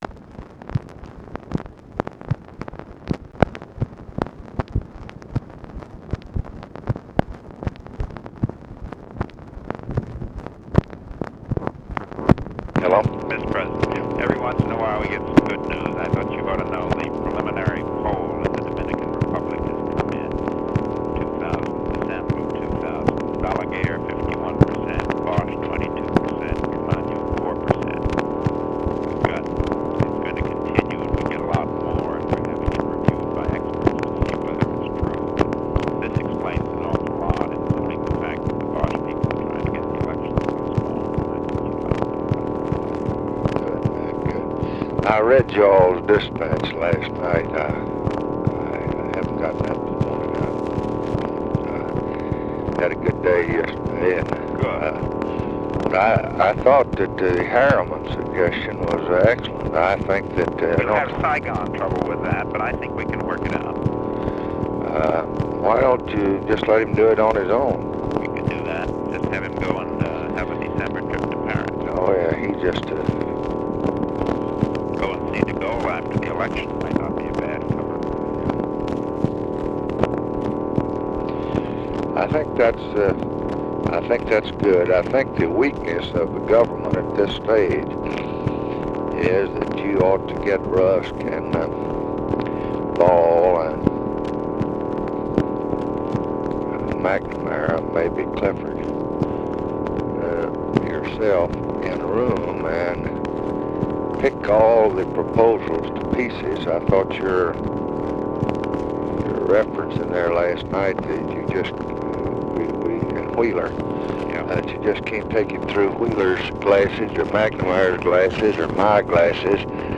Conversation with MCGEORGE BUNDY, December 3, 1965
Secret White House Tapes